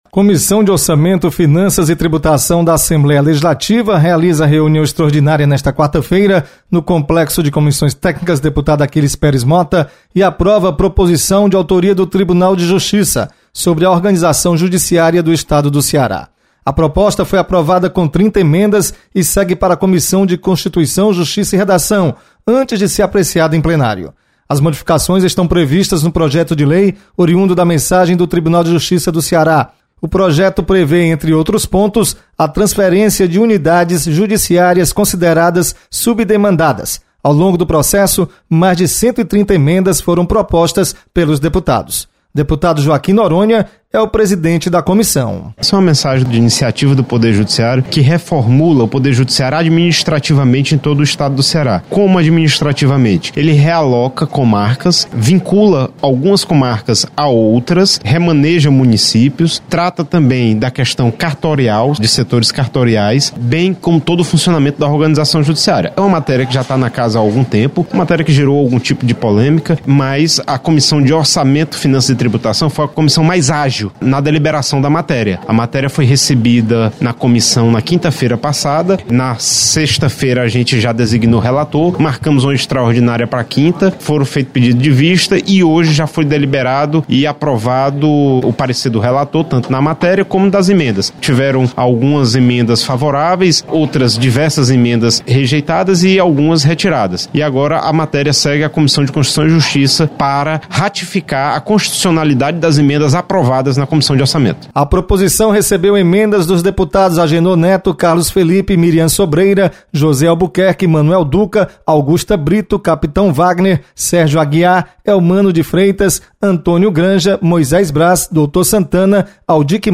Você está aqui: Início Comunicação Rádio FM Assembleia Notícias Comissão